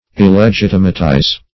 Search Result for " illegitimatize" : The Collaborative International Dictionary of English v.0.48: Illegitimatize \Il`le*git"i*ma*tize\, v. t. To render illegitimate; to bastardize.
illegitimatize.mp3